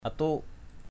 /a-tu:ʔ/